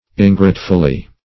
-- In"grate`ful*ly, adv. -- In"grate`ful*ness, n.